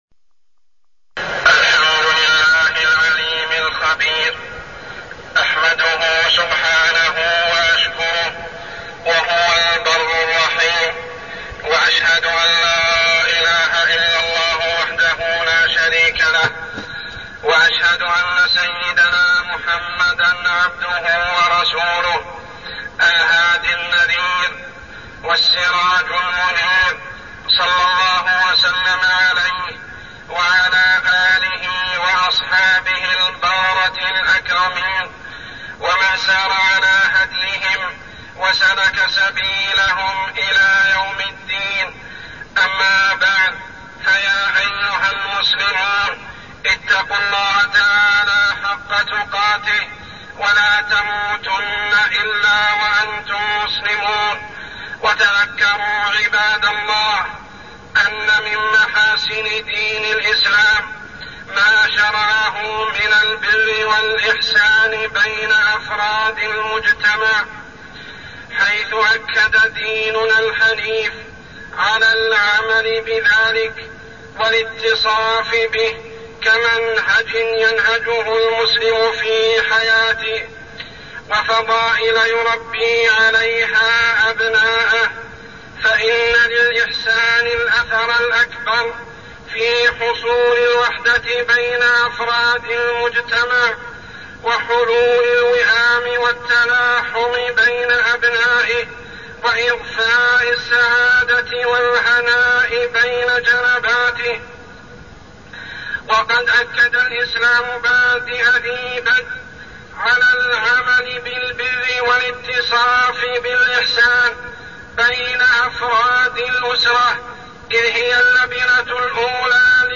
تاريخ النشر ١١ محرم ١٤١٦ هـ المكان: المسجد الحرام الشيخ: عمر السبيل عمر السبيل الإحسان إلى الوالدين